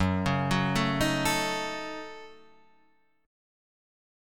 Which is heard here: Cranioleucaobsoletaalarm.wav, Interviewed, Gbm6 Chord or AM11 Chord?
Gbm6 Chord